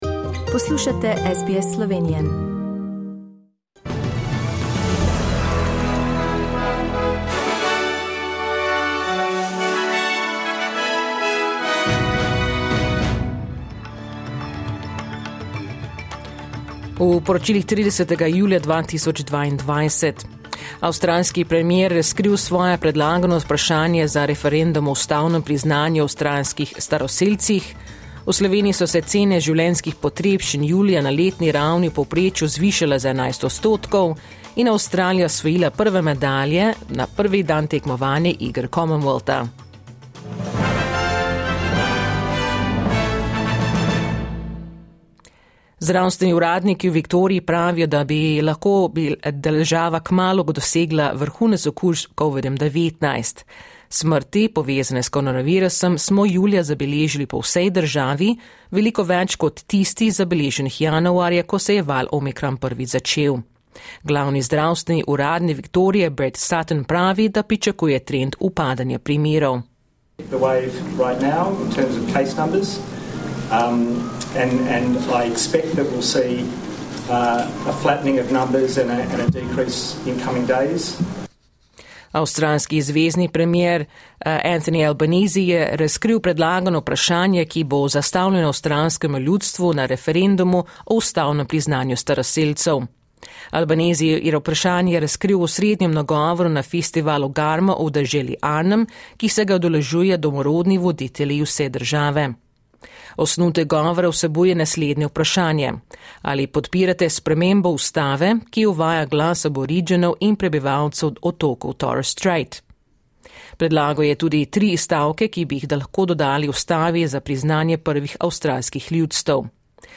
Poročila Radia SBS v slovenščini 30.julija